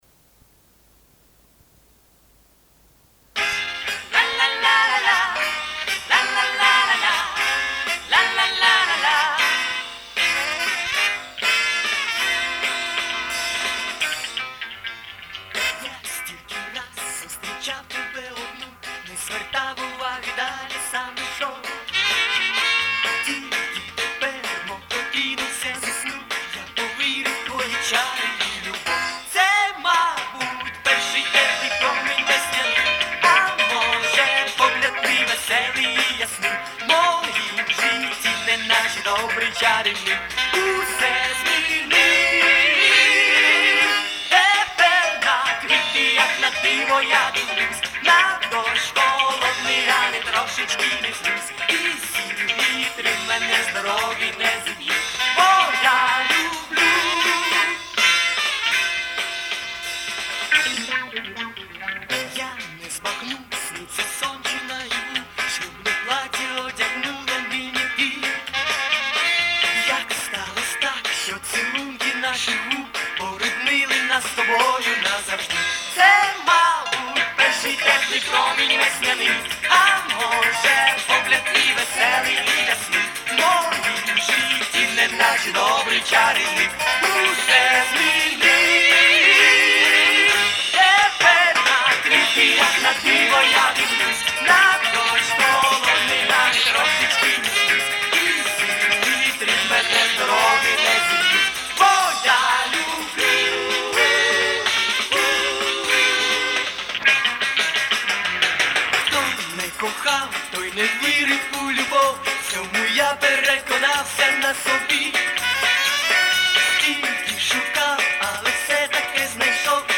концертная запись